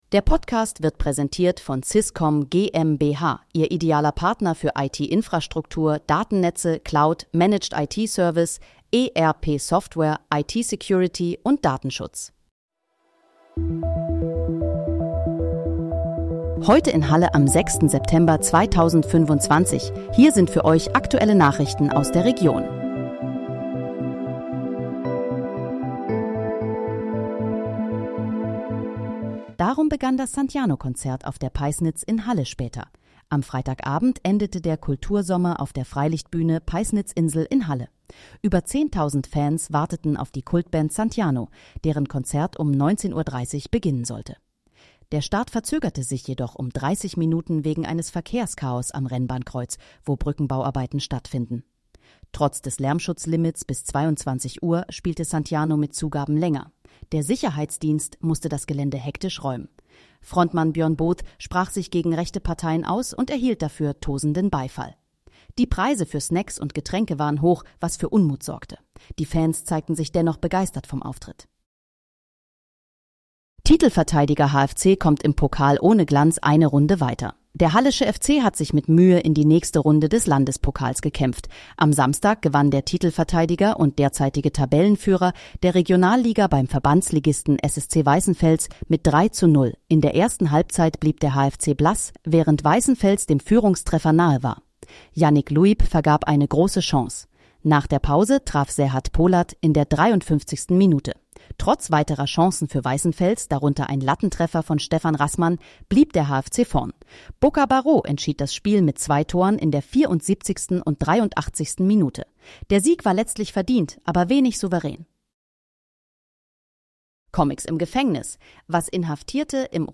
Heute in, Halle: Aktuelle Nachrichten vom 06.09.2025, erstellt mit KI-Unterstützung
Nachrichten